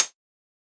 UHH_ElectroHatC_Hit-08.wav